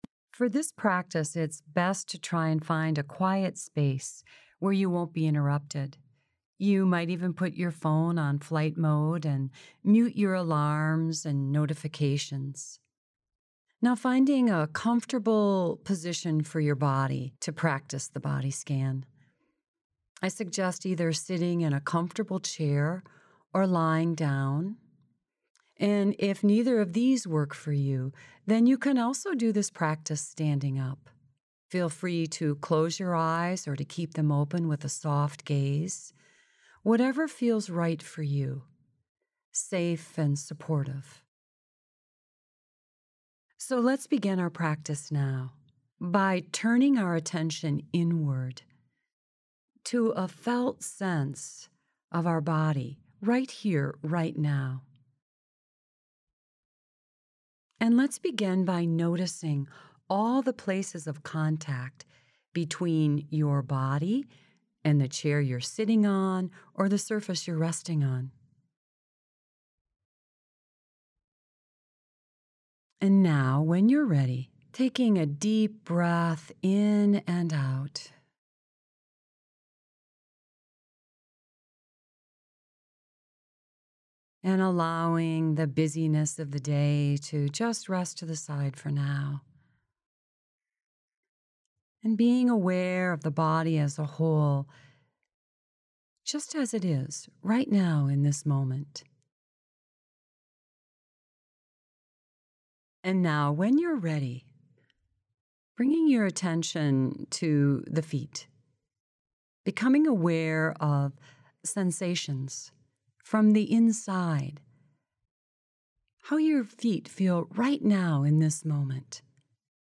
10-minute_bodyscan_meditation.mp3